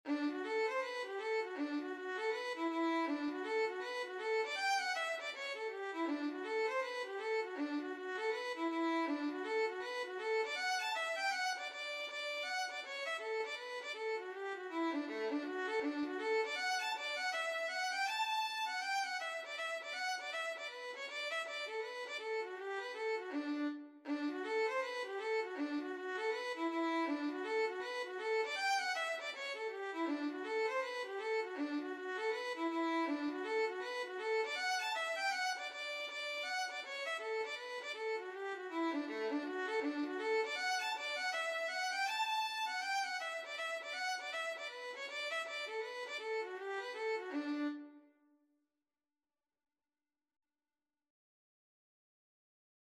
Traditional Trad. Early Rising (Irish Folk Song) Violin version
4/4 (View more 4/4 Music)
D major (Sounding Pitch) (View more D major Music for Violin )
Violin  (View more Intermediate Violin Music)
Traditional (View more Traditional Violin Music)
Irish